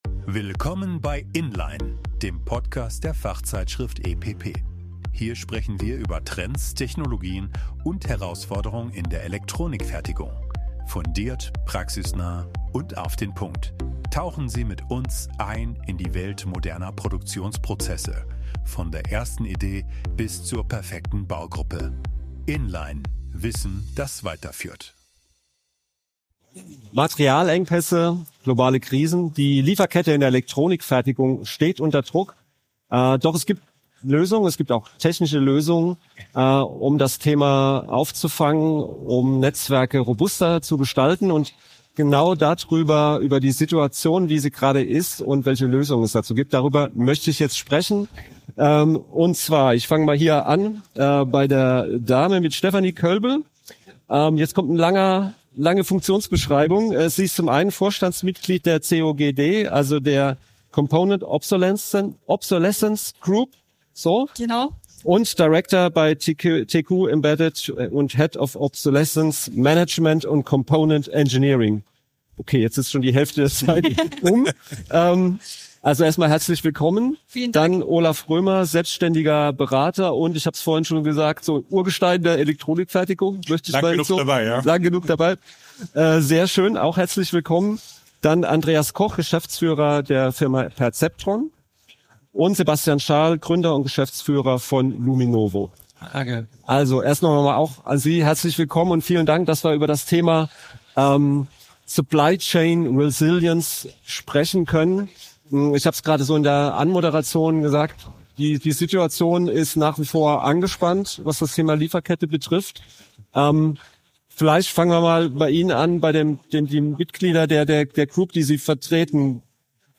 In dieser Folge diskutieren Experten, unter anderem wie Unternehmen Risiken wie Materialknappheit oder Obsoleszenz minimieren, welche digitalen Tools und KI-Anwendungen helfen und wie ESG-Vorgaben und CO₂-Reporting die Lieferketten beeinflussen.